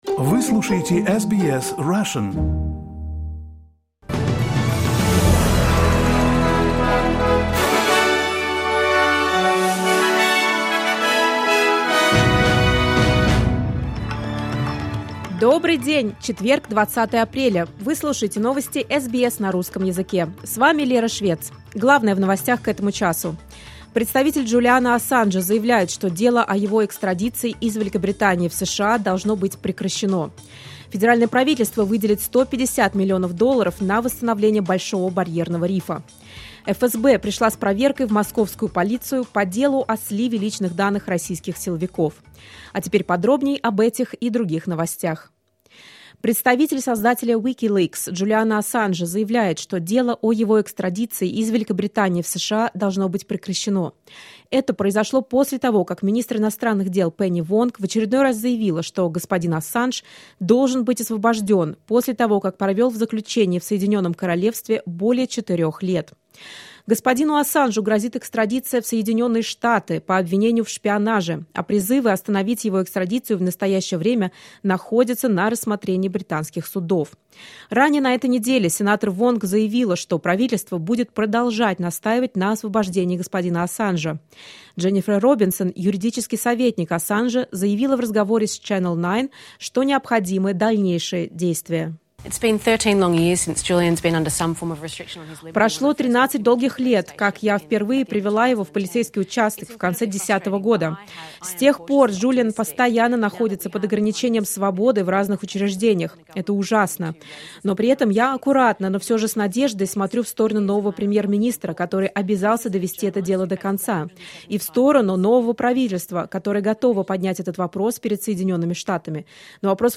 SBS news in Russian — 20.04.2023